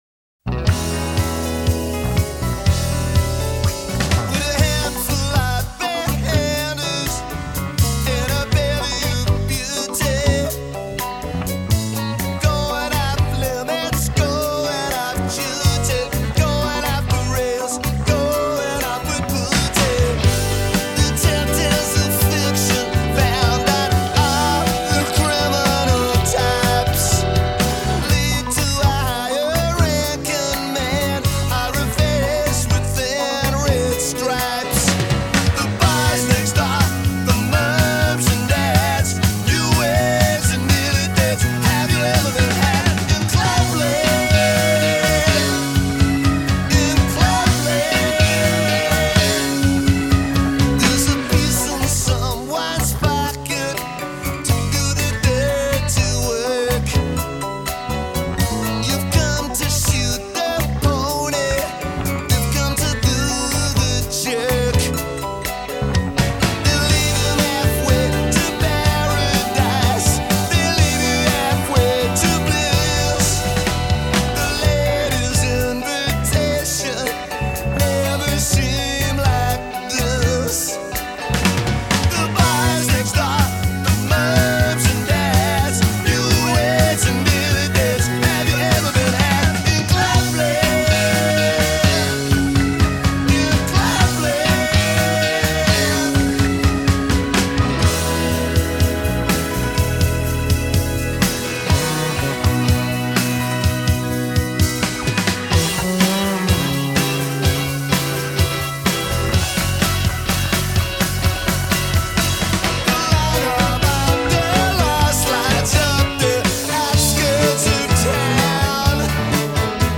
Just listen to those drums, that piano, those lyrics!